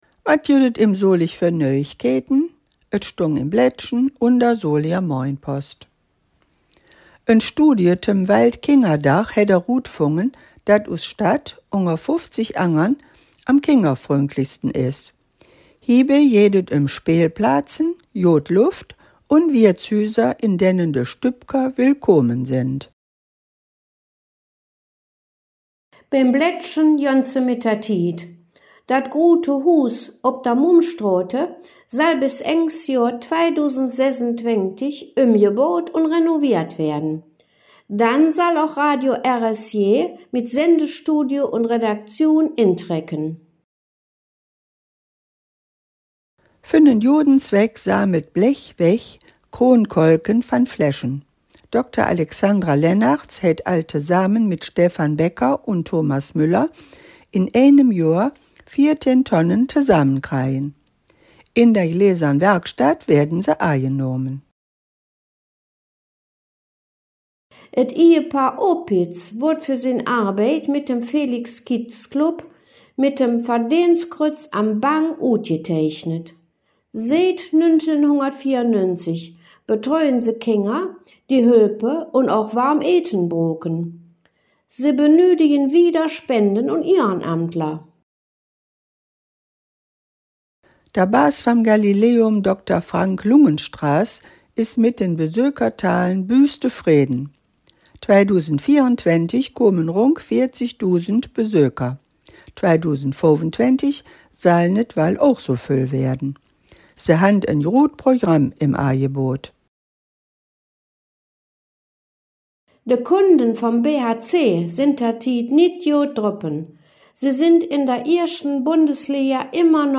Solinger Platt ist die traditionell in Solingen gesprochene Sprache.
Bei der Studiowelle ist die Mundart regelmäßig in den Solinger Platt-Nachrichten zu hören.
solinger-platt-nachrichten-does-weeke-em-solig-25-40.mp3